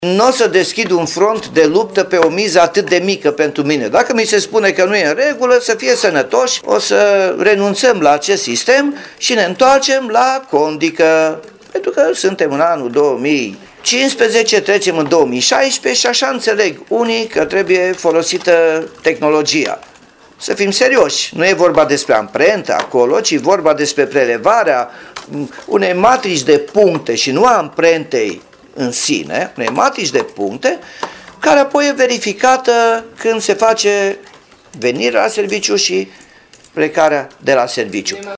Primarul Nicolae Robu a spus că pentru pontarea angajaților o să revină la condica pe hârtie: